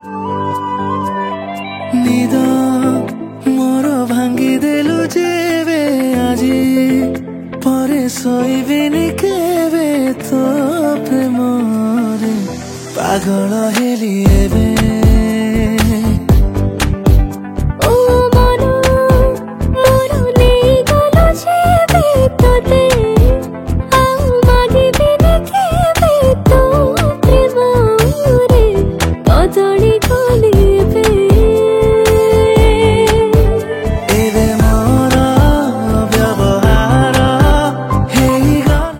Odia Album Ringtones
Romantic song